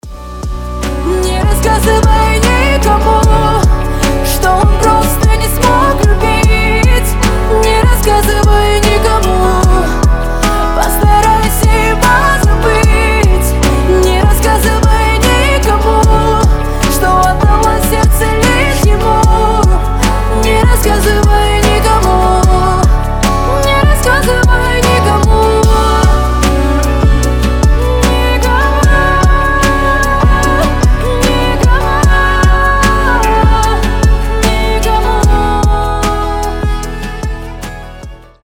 • Качество: 320, Stereo
гитара
лирика
скрипка
медленные
красивый женский голос
красивый вокал